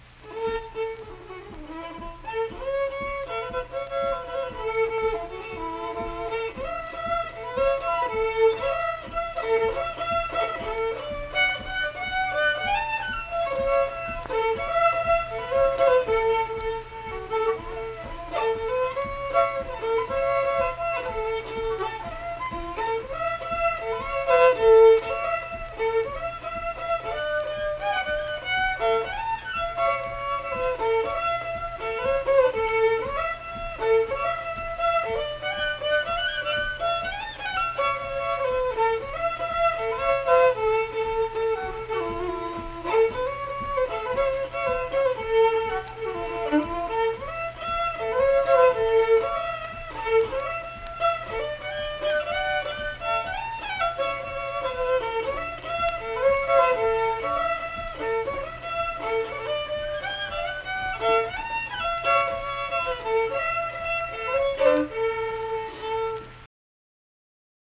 Unaccompanied fiddle performance
Instrumental Music
Fiddle
Tyler County (W. Va.), Middlebourne (W. Va.)